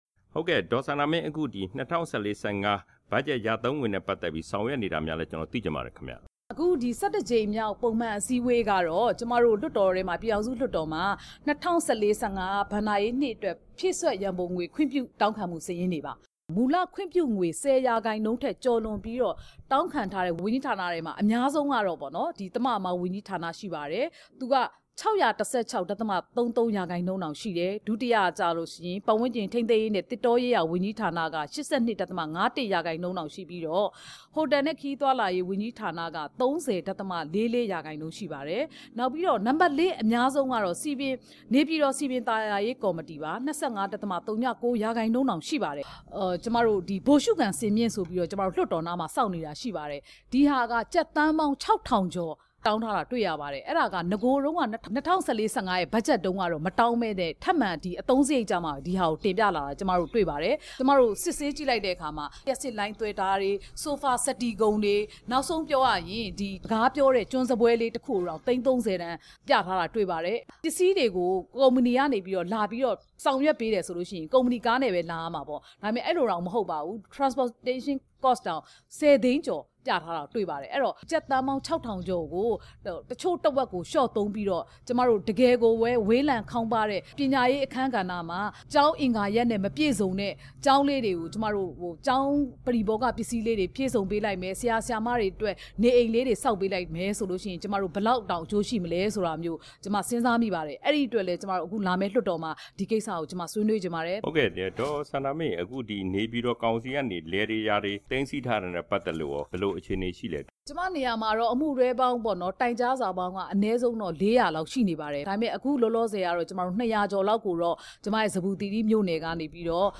လွှတ်တော်ကိုယ်စားလှယ် ဒေါ်စန္ဒာမင်းကို မေးမြန်းချက်